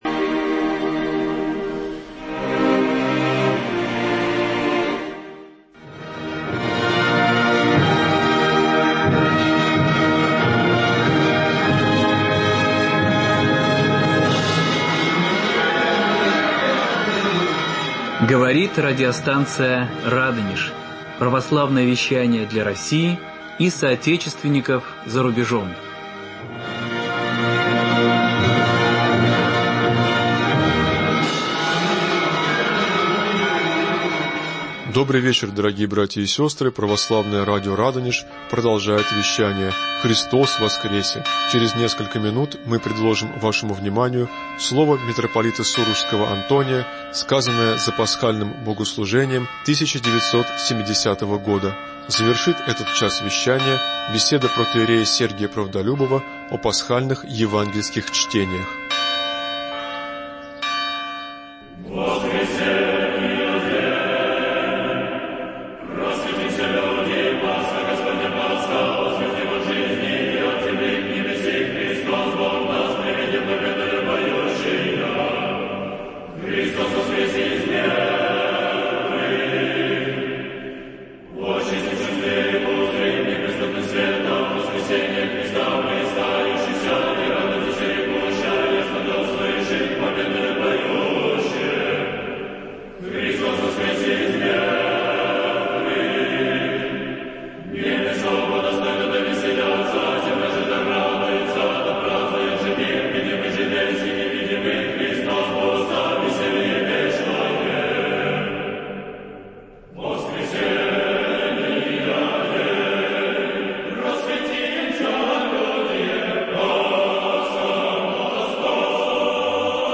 Беседа о Пасхальных Евангельских чтениях